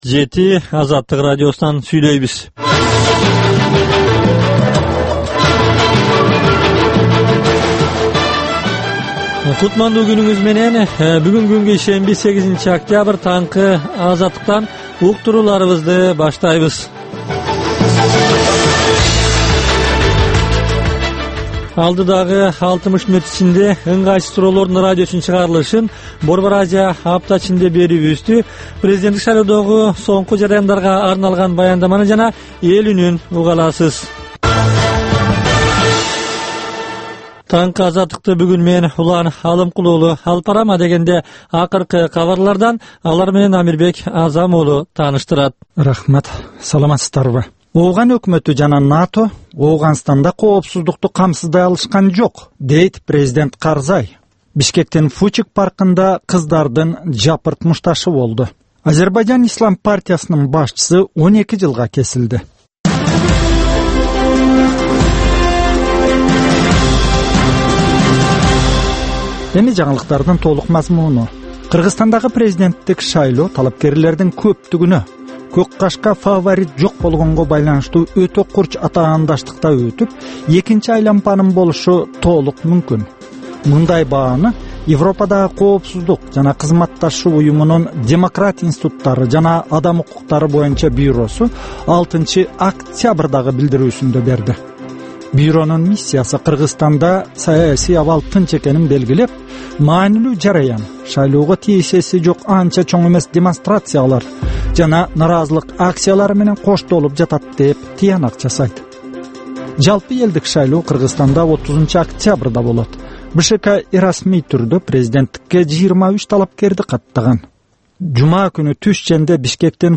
Таңкы 7деги кабарлар